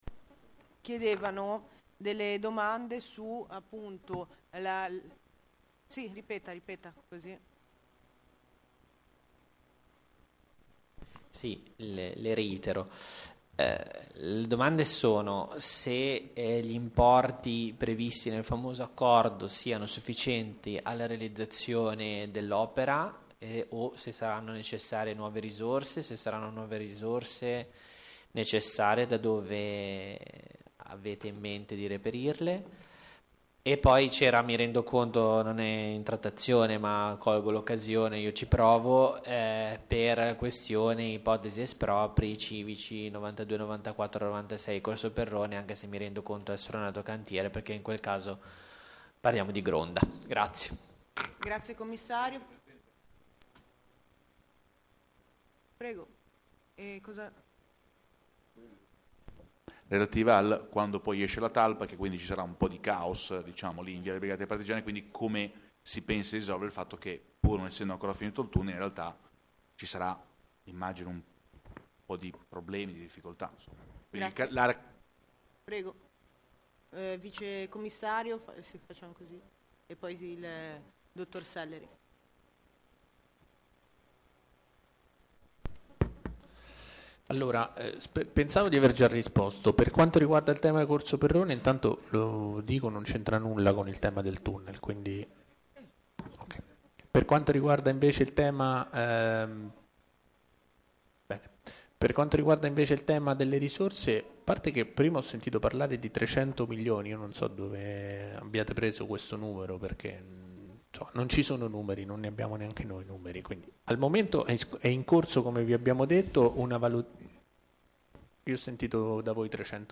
Sedute del Consiglio Comunale
Via Garibaldi 9, 16124